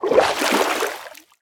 latest / assets / minecraft / sounds / liquid / swim11.ogg
swim11.ogg